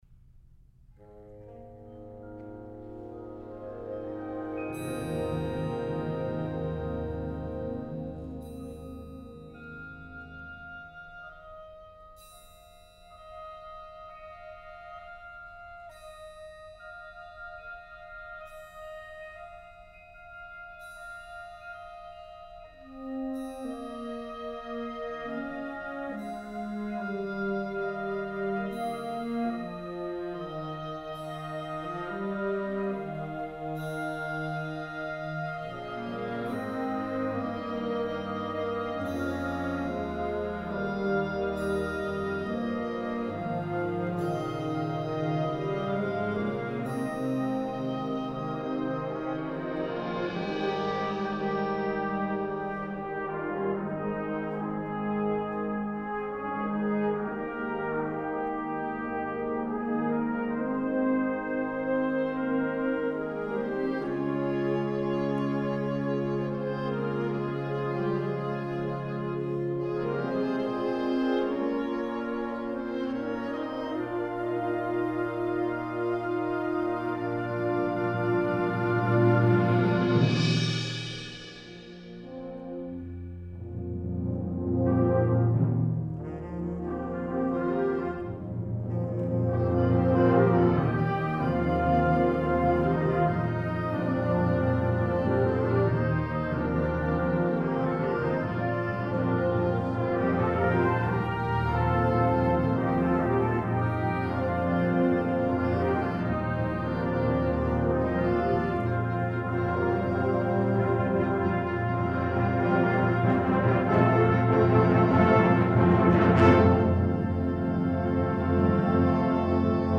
Genre: Band
a inspiring ballad
Timpani (3 drums)
Vibraphone